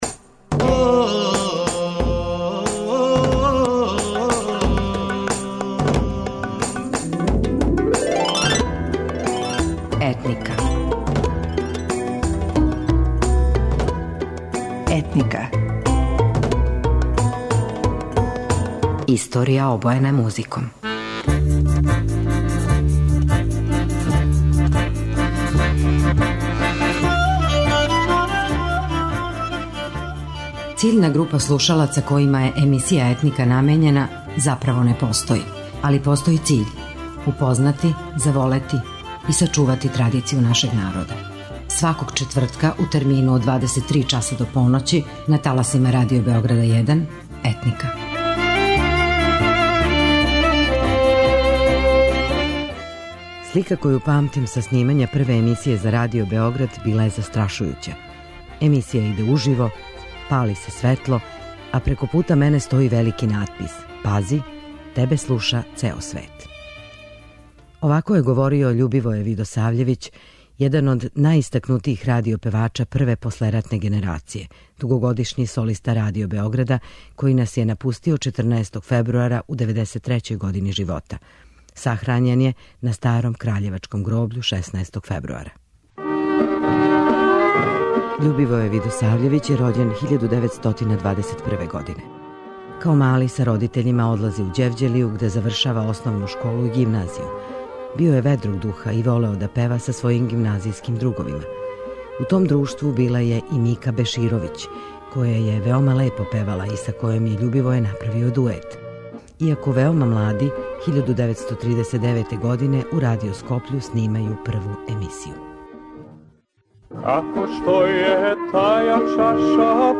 У емисији су коришћени снимци архива Радио Београда и емисије „Трезор“.